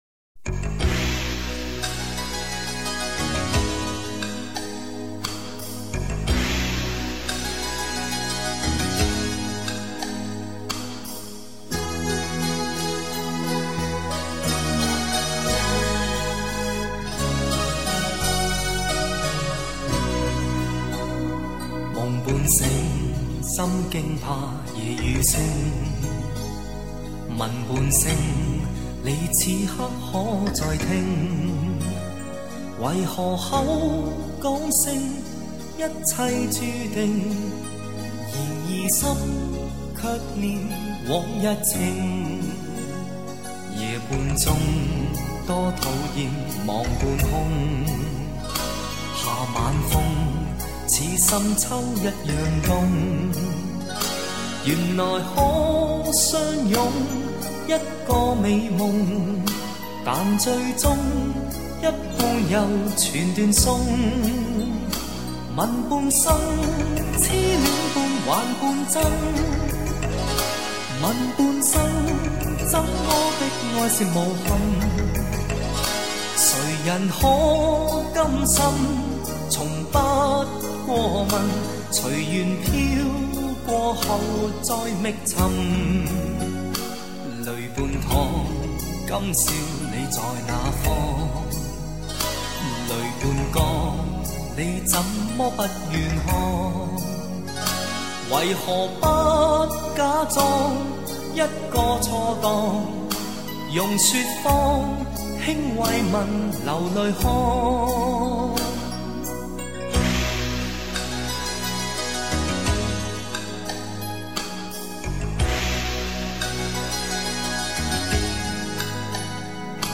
清纯的声音 清新的感觉 优美的和声